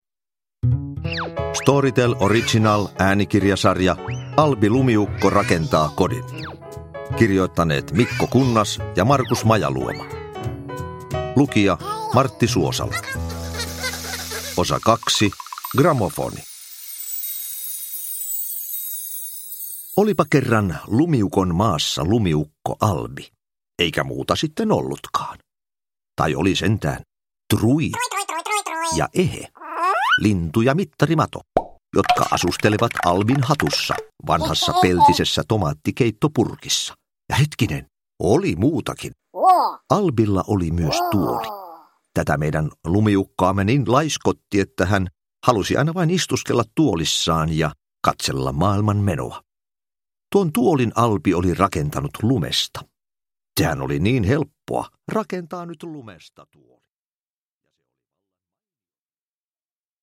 Albi rakentaa kodin: Gramofoni – Ljudbok – Laddas ner
Uppläsare: Martti Suosalo